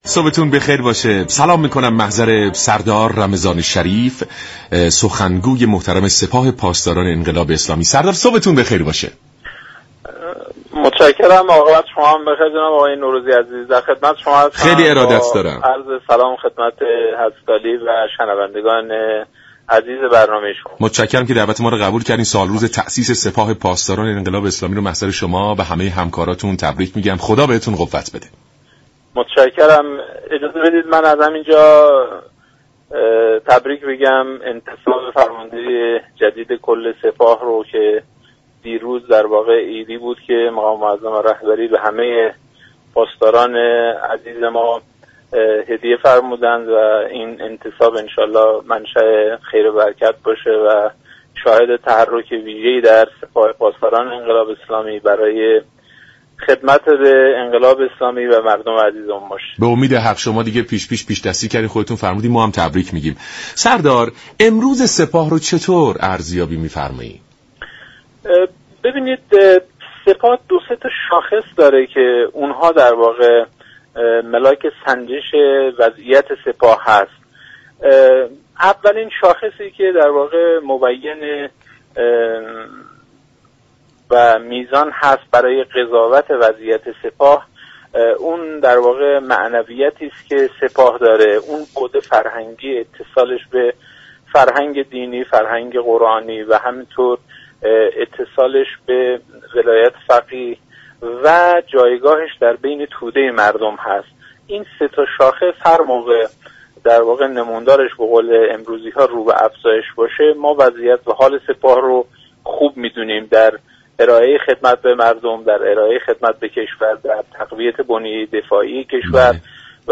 سردار رمضان شریف سخنگوی سپاه پاسداران انقلاب اسلامی در گفت و گو با برنامه "سلام صبح بخیر" رادیو ایران به ارزیابی وضعیت فعلی سپاه پاسداران انقلاب اسلامی پرداخت و گفت: اتصال به فرهنگ دینی و قرآنی، پایبندی به اصل ولایت فقیه و جایگاهش در میان توده مردم سه شاخص مهم ارزیابی وضعیت فعلی سپاه پاسداران اسلامی است.